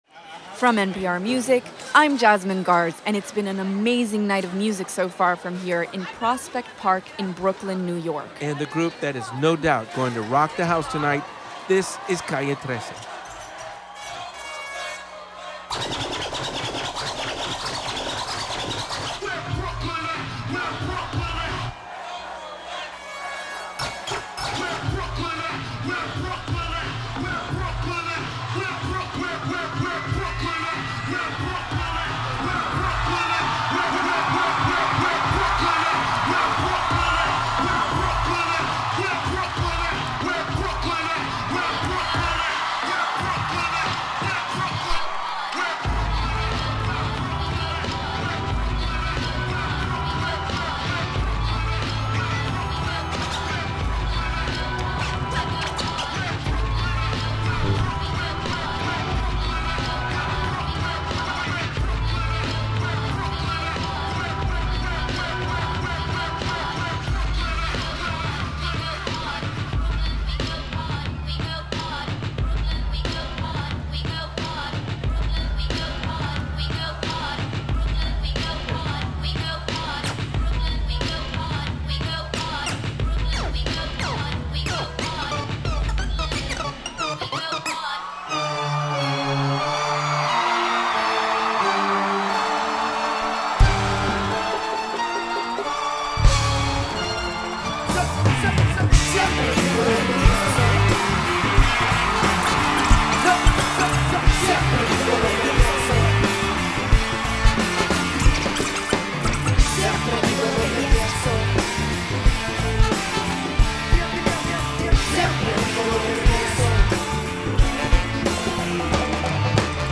As usual, the Caribbean rappers didn't disappoint.